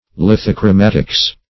Search Result for " lithochromatics" : The Collaborative International Dictionary of English v.0.48: Lithochromatics \Lith`o*chro*mat"ics\ (-kr[-o]*m[a^]t"[i^]ks), n. See Lithochromics .